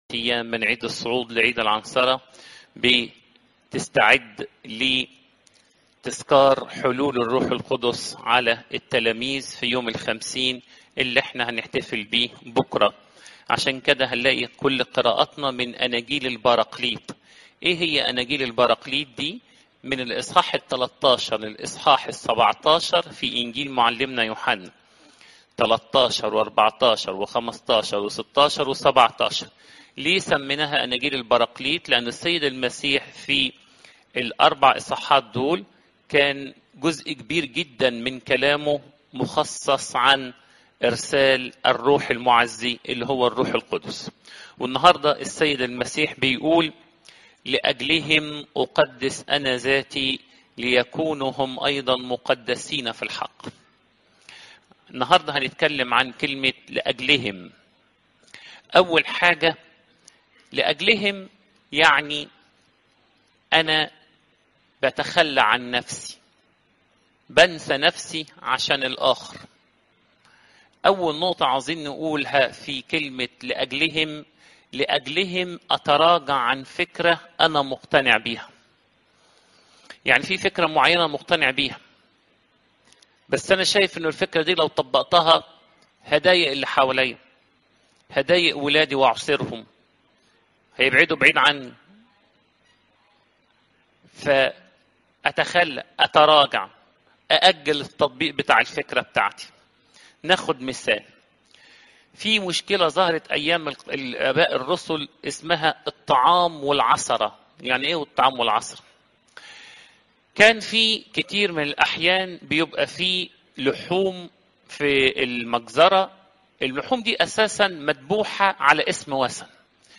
عظات قداسات الكنيسة (يو 7 : 14 - 24)